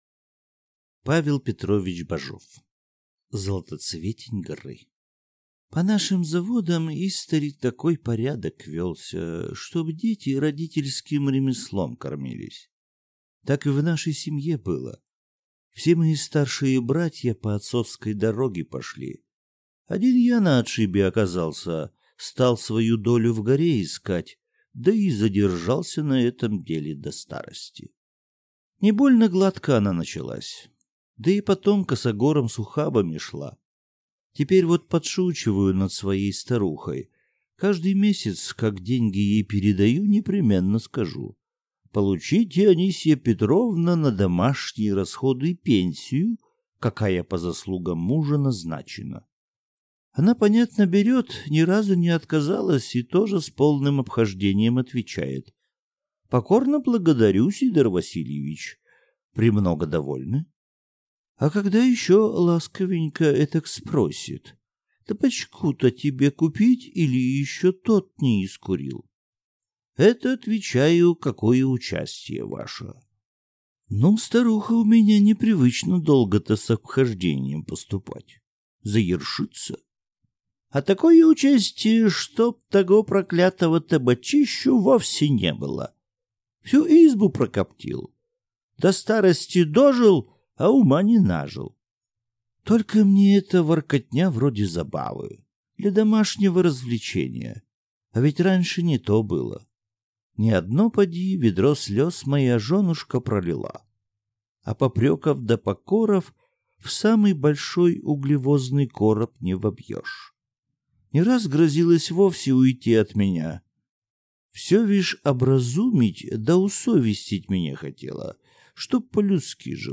Аудиокнига Золотоцветень горы | Библиотека аудиокниг